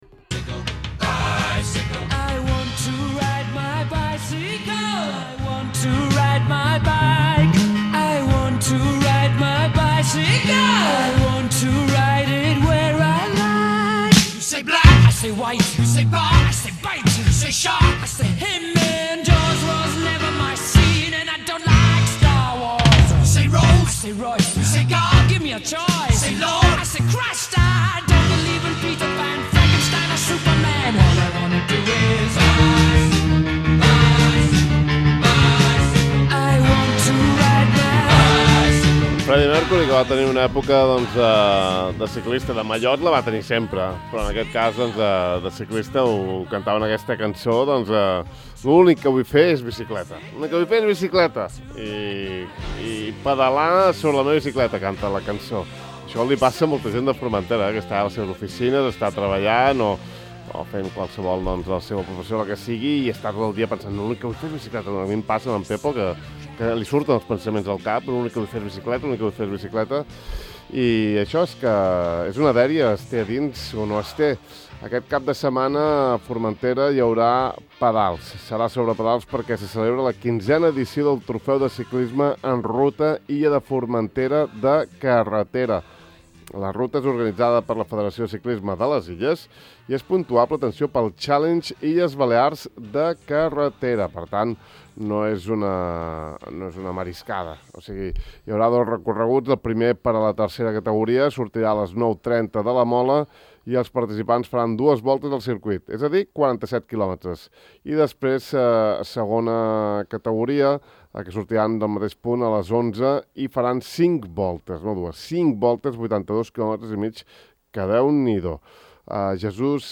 En aquesta entrevista a Ràdio Illa